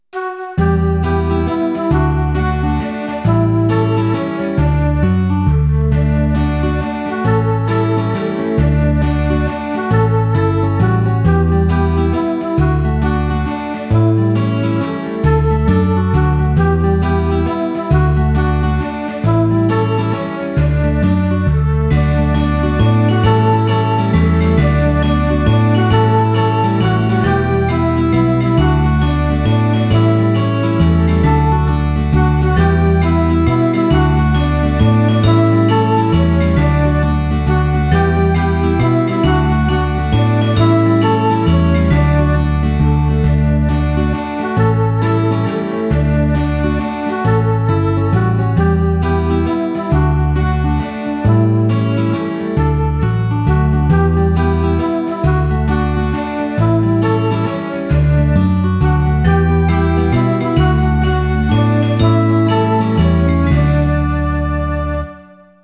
ein bekanntes deutsches Volkslied